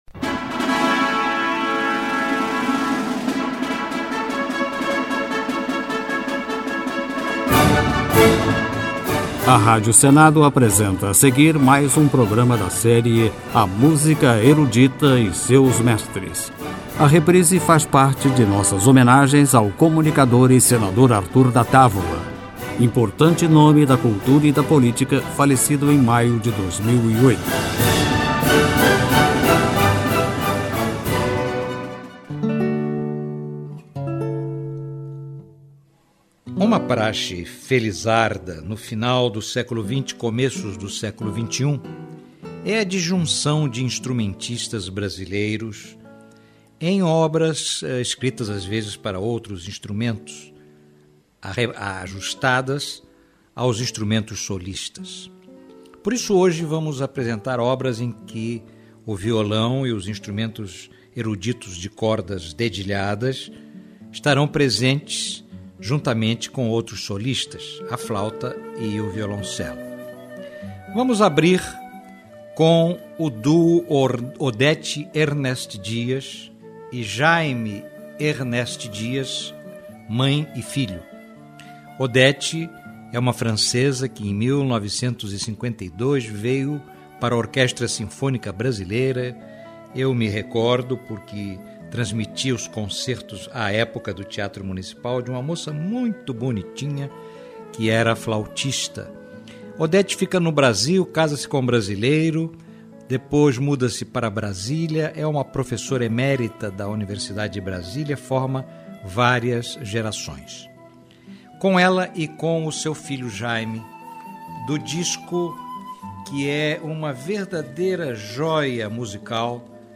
Música Erudita
Música Clássica